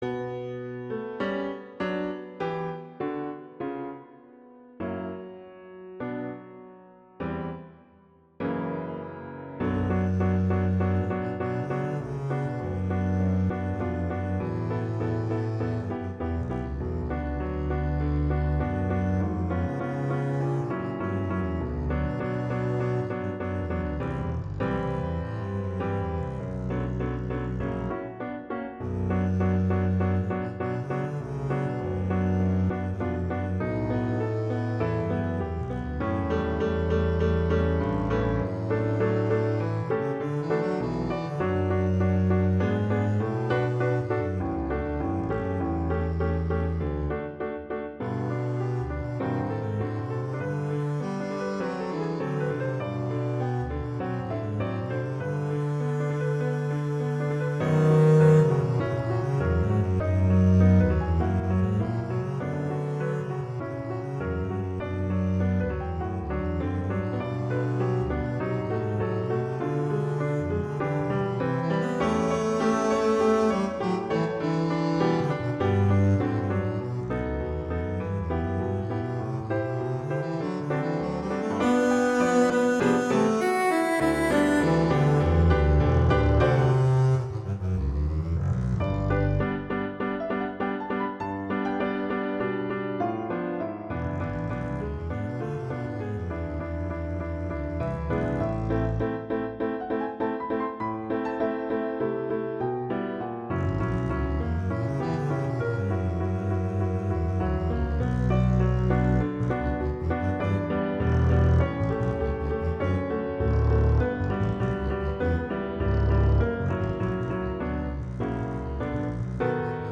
Instrumentation: double-bass & piano
classical, concert
B minor, E minor